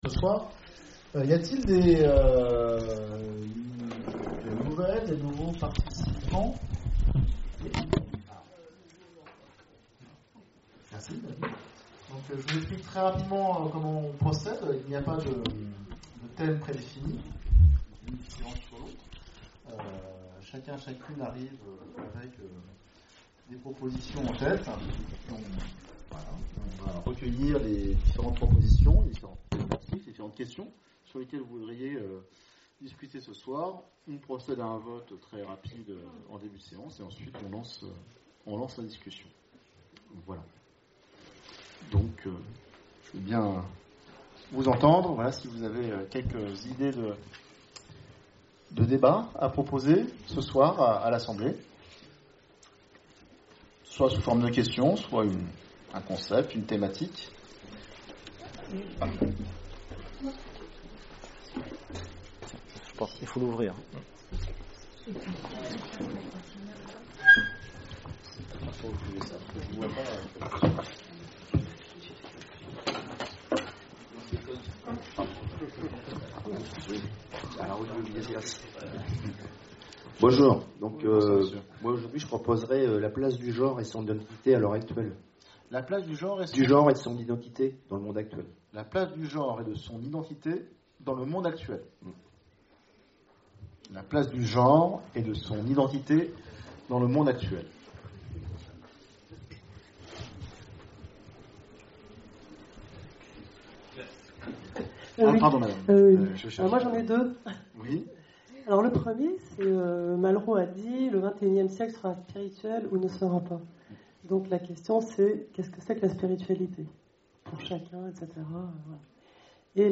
Conférences et cafés-philo, Orléans
CAFÉ-PHILO PHILOMANIA Faut-il briser tous les tabous ?